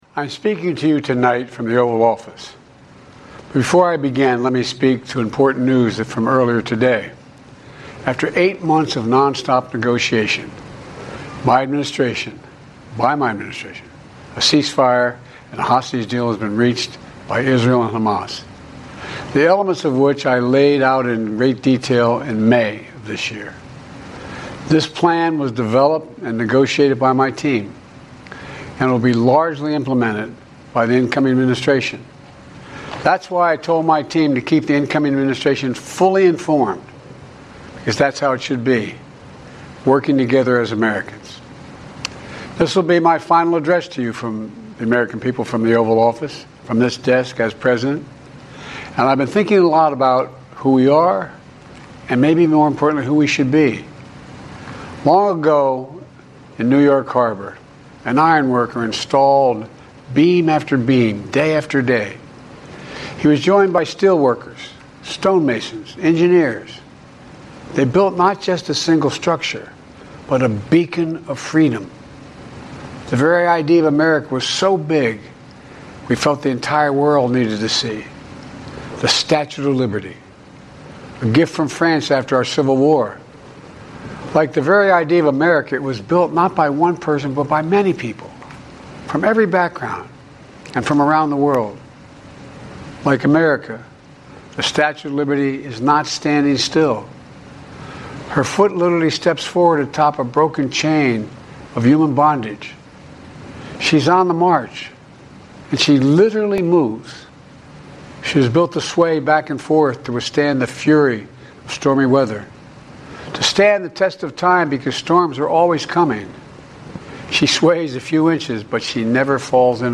Hear audio of President Biden’s farewell address; view video, read transcript